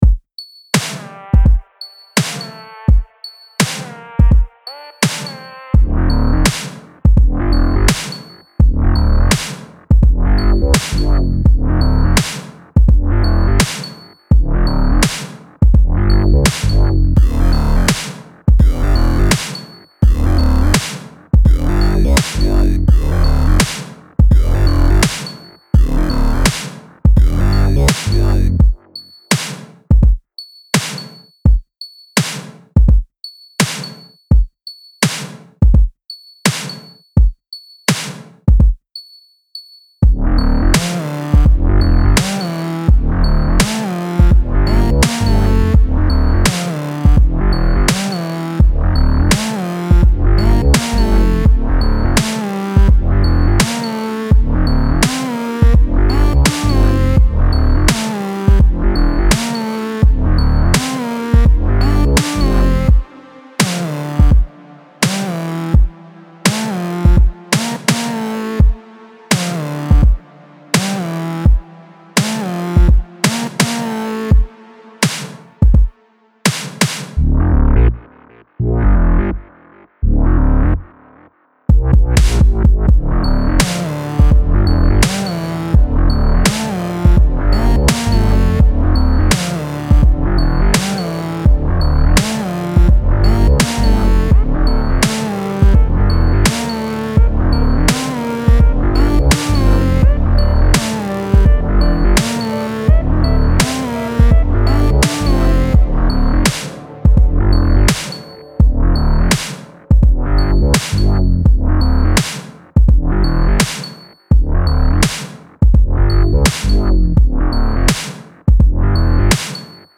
Style Style Hip-Hop
Mood Mood Cool, Dark, Driving +1 more
Featured Featured Bass, Drums, Synth
BPM BPM 84